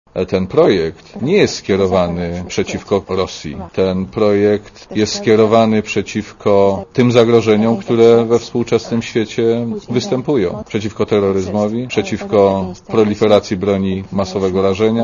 Dla Radia Zet mówi Jerzy Szmajdziński (59 KB)